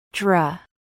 This R-blend says: /dr/, /dr/, /dr/, drum.
The D in the DR blend is most commonly said at the beginning with a /dr/ as in “drum” and not with the clearly articulated sound as in “dog”.
DR-drum-phoneme-name-AI-v2.mp3